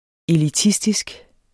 Udtale [ eliˈtisdisg ]